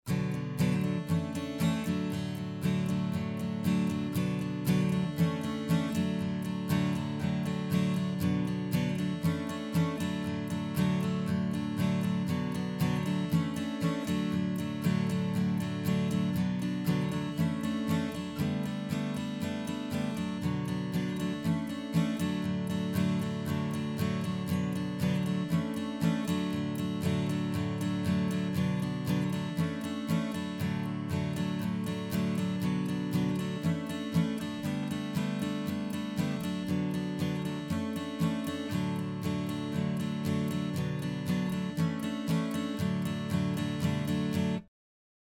"Piepen" bei A-Gitarren Aufnahme
Hallo, Folgendes Problem: Was ist das für eine Resonanz die sich bei ca. 3500 Hz als rhytmisches Piepen bemerkbar macht (deutlich ab 15 Sekunden) und wie kann man das bei der Aufnahme vermeiden? Setup: 2x KM184 in XY ca. 20-25cm von der Gitarre weg. Gitarre ist eine Jumbo in Open D. Vielen Dank!